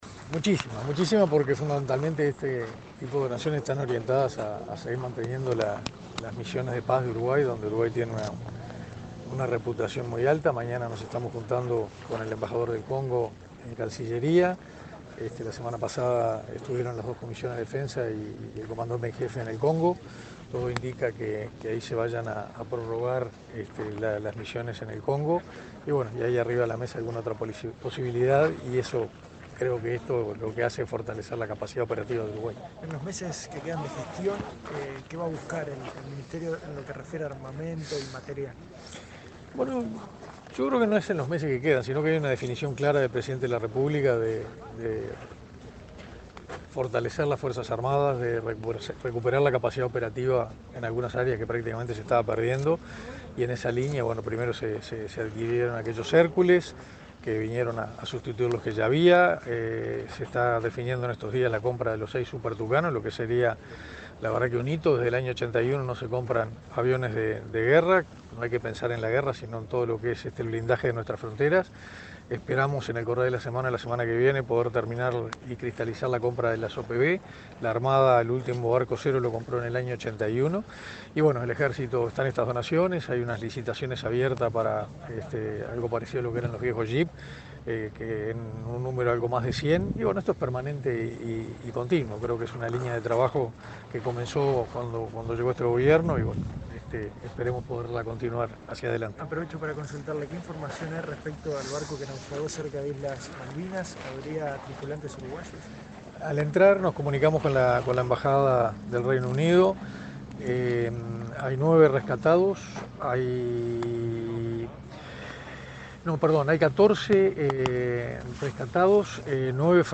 Declaraciones del ministro de Defensa Nacional, Armando Castaingdebat
Este miércoles 24, en Montevideo, el ministro de Defensa Nacional, Armando Castaingdebat, fue entrevistado para medios periodísticos, luego de